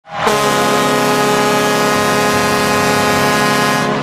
Blackhawks Goal Horn Sound Effect Free Download
Blackhawks Goal Horn